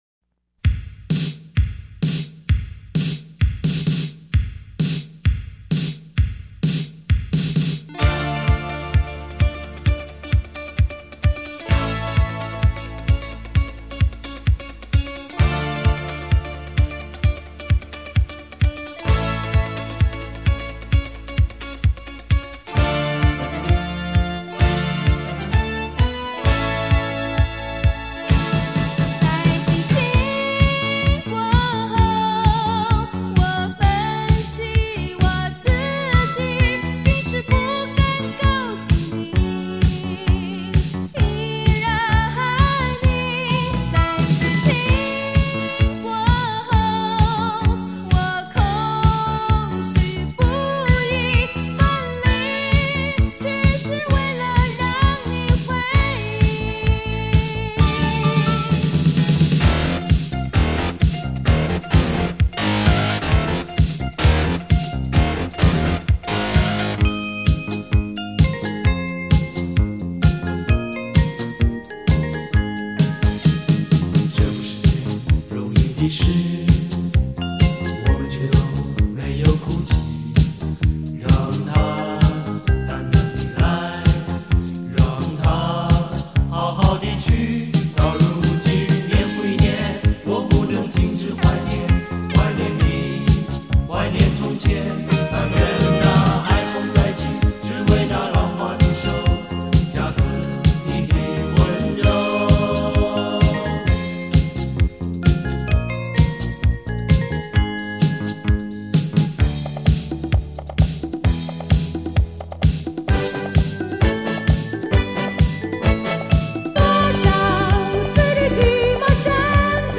最多的流行的歌曲